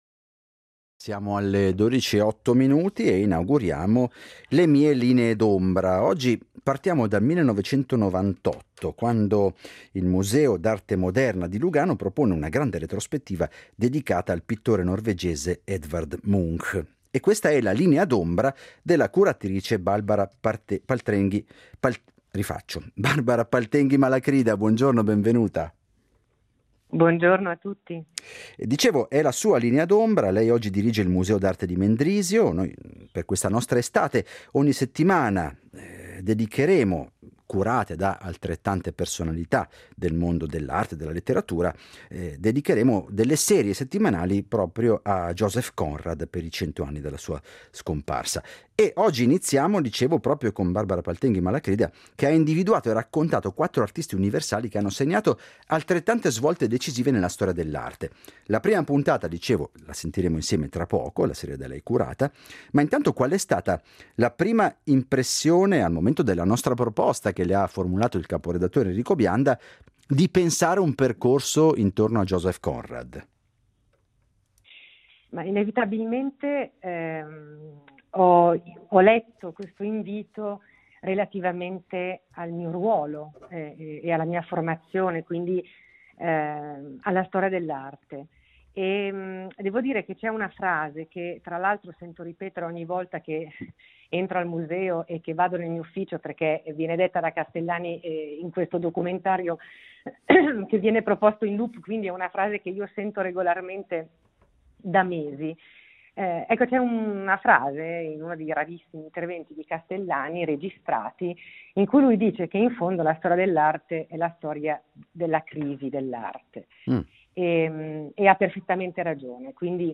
Intervista alla storica e critica dell’arte